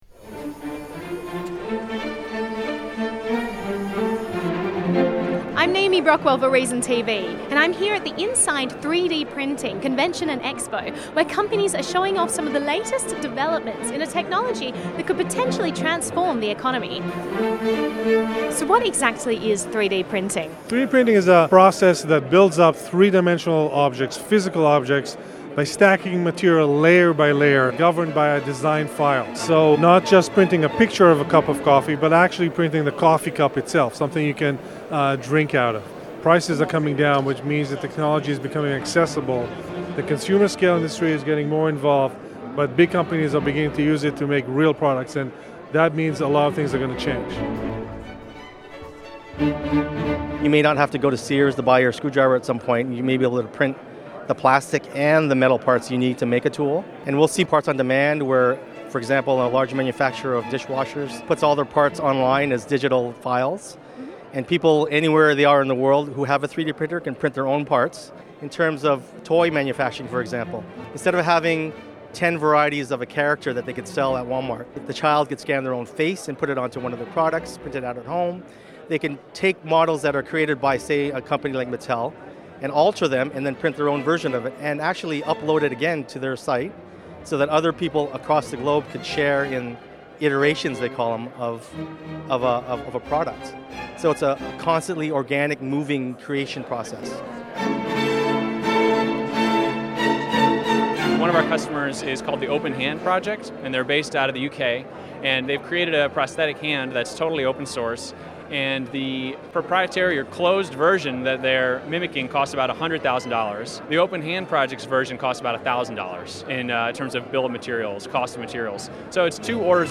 On April 4, 2014, Reason TV attended the Inside 3D Printing Conference and Expo, a three-day event held at the Jacob Javits Convention Center in New York City.